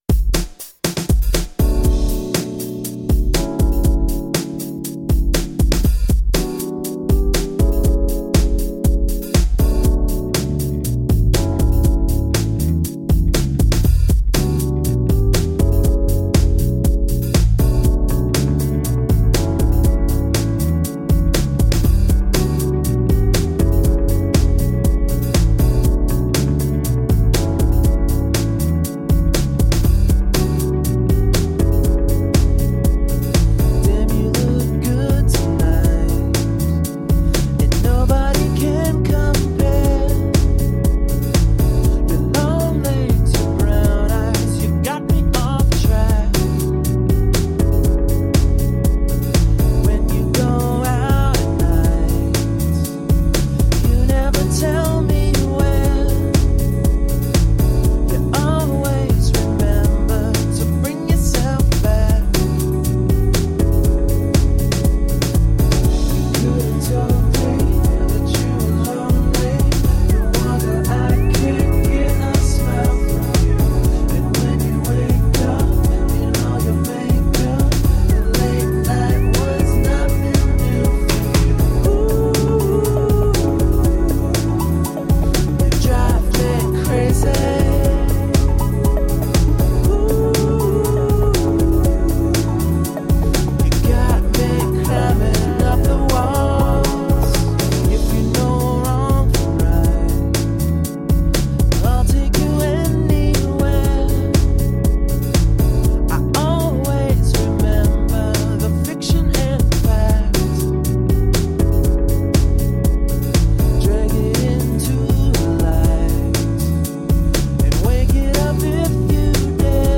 Soulful electro pop.